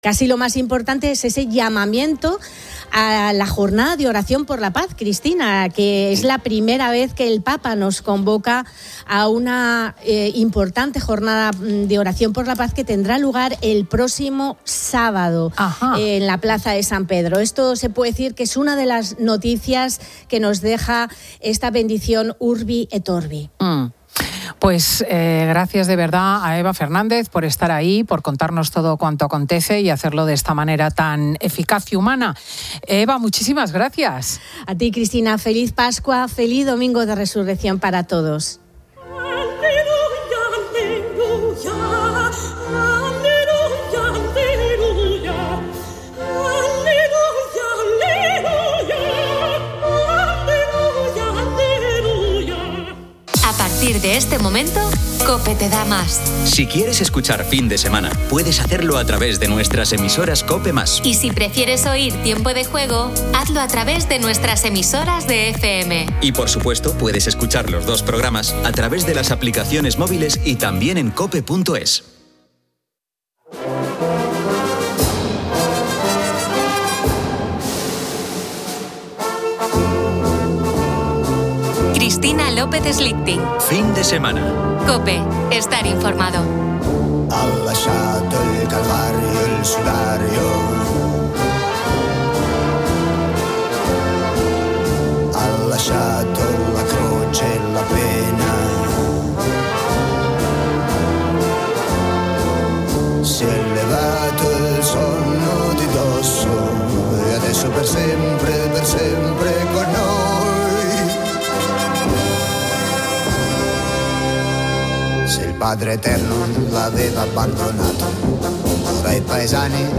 El programa destaca el llamamiento del papa a una jornada de oración por la paz, que tendrá lugar el próximo sábado en la Plaza de San Pedro, considerado uno de los temas más importantes. Una tertulia central explora las sorpresas de la vida, con experiencias personales y de los oyentes, abarcando desde nacimientos y regalos inesperados hasta anécdotas conmovedoras sobre reencuentros o aversiones a las sorpresas. Además, se ofrece una práctica receta de tortilla tuneada de guisantes para cenas rápidas y se informa sobre la 'Operación Retorno' en las carreteras españolas, alertando de puntos conflictivos en varias autovías hacia Madrid.